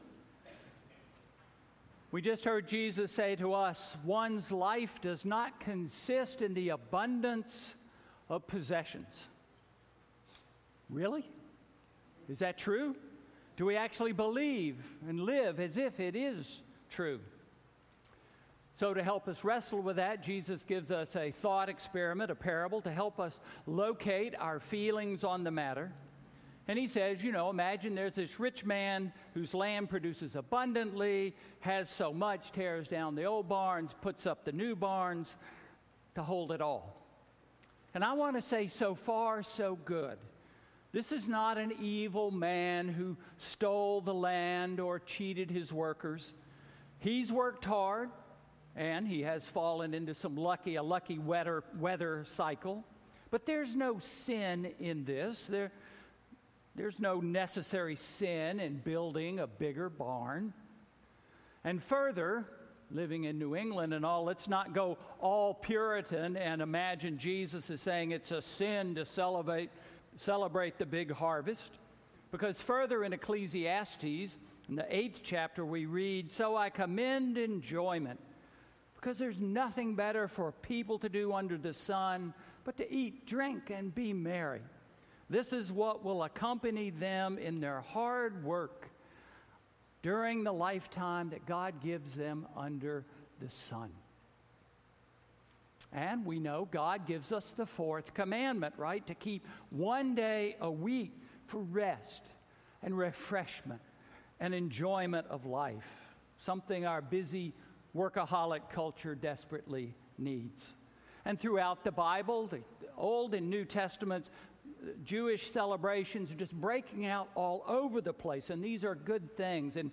Sermon–What is My Life? August 4, 2019